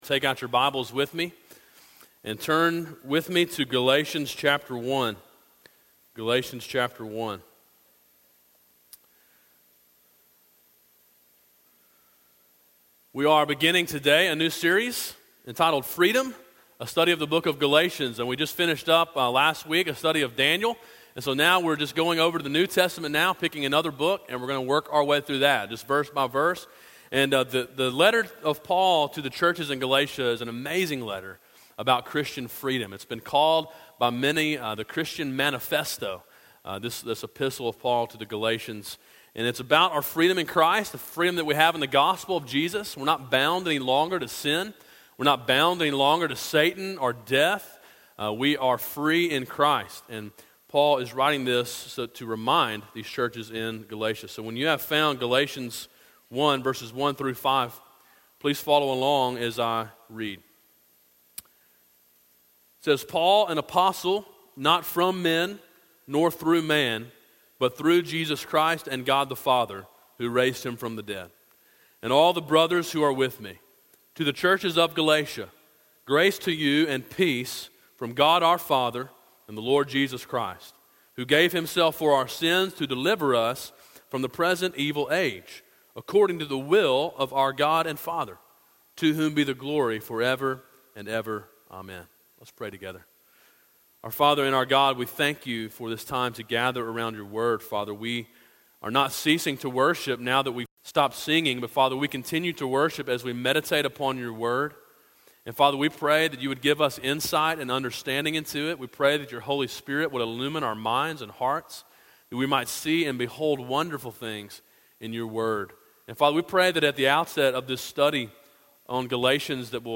A sermon in a series titled Freedom: A Study of Galatians.